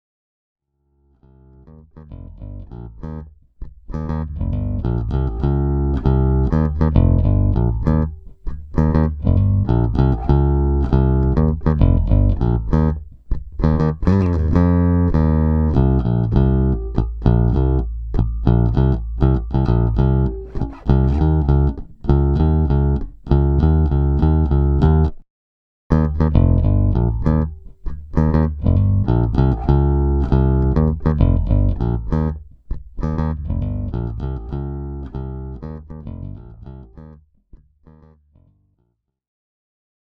Basson herkullisesta akustisesta äänestä syntyy erittäin dynaaminen ja vahva sähköinen olemus. Sterlingin etuvahvistin on yllättävän vähäkohinainen, ja uutukaisen taajuuskorjaimet toimivat hyvin musikaalisesti (itse asiassa jopa paremmin kuin ensimmäisten 70-luvun Musarien vastineet).
Pätkät on äänitetty suoraan äänikortin kautta sekvensseriin ilman lisä-EQ:ta: